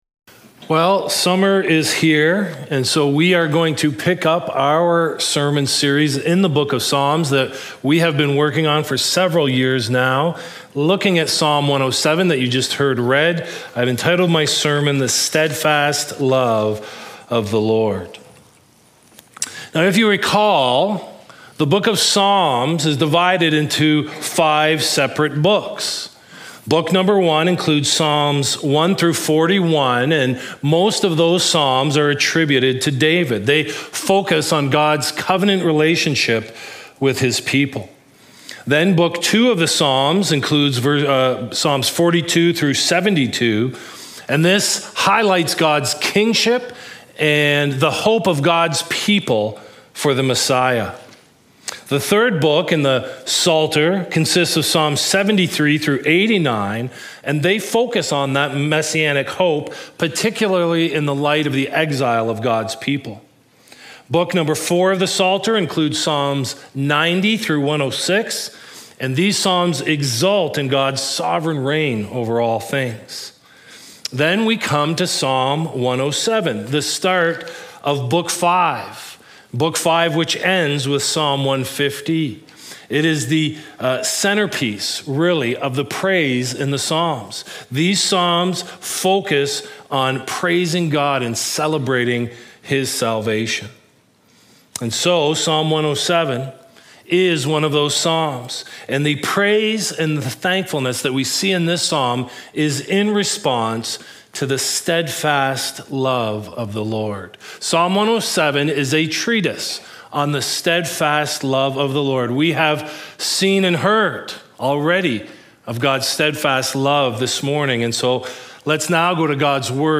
Recorded live from The Barn Studio